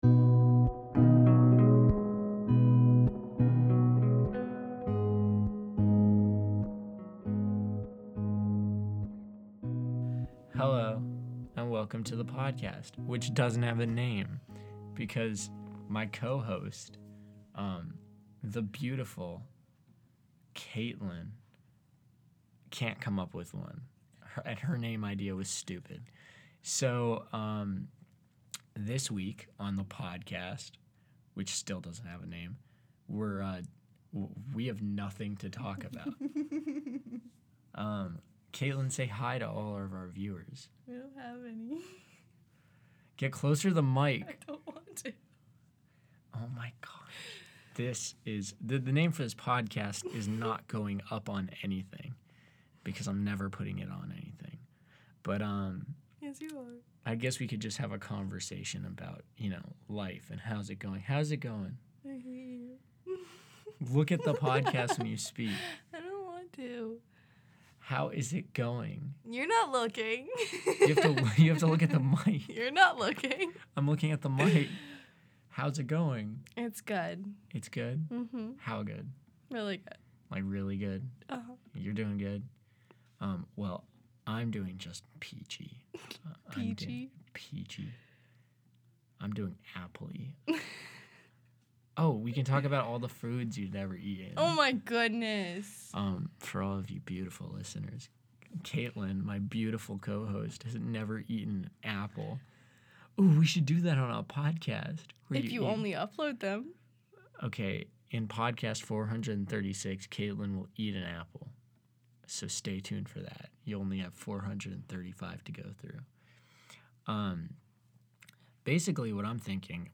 We talk about nothing, everything, and my voice sounds funny.